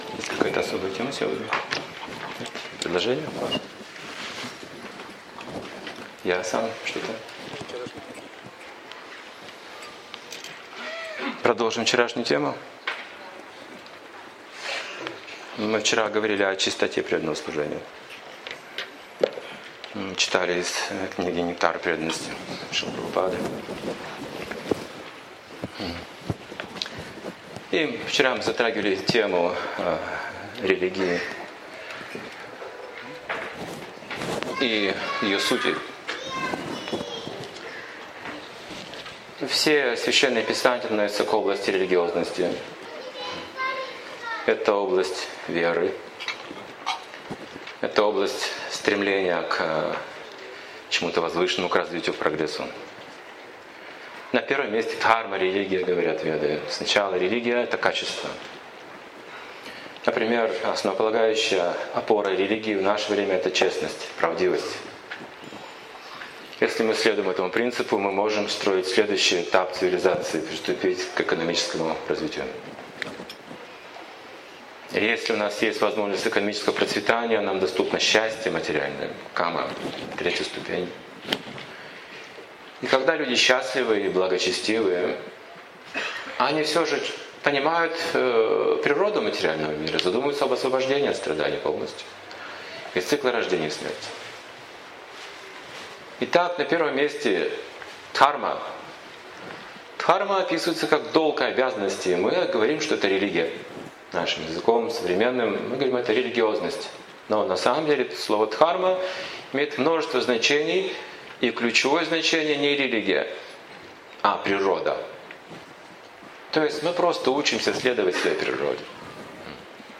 Лекция о чистоте преданного служения, описывающая различные уровни и аспекты служения. В рамках раскрытия природы личности и ее истинного положения в этом мире лектор излагает транцендентное знание вед.